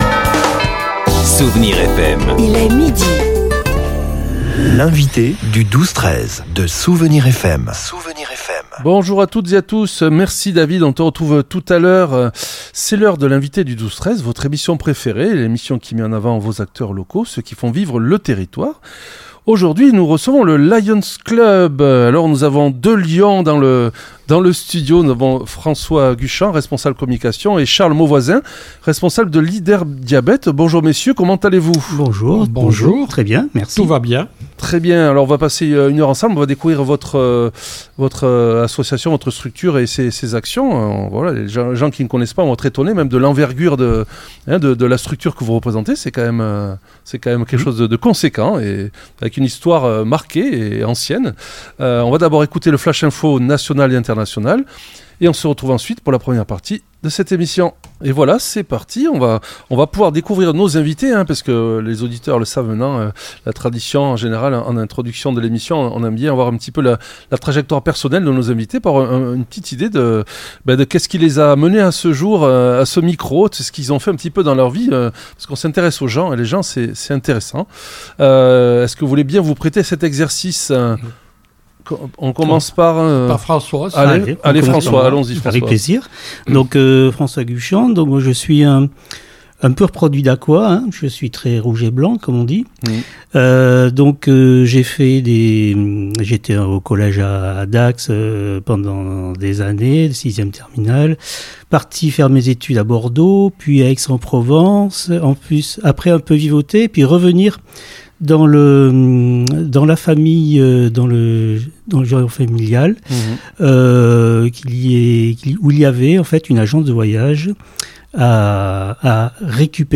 Si le club intervient sur de multiples fronts — comme la Bibliothèque sonore ou le soutien aux sinistrés des inondations — l'entretien a mis l'accent sur une urgence de santé publique : la lutte contre le diabète.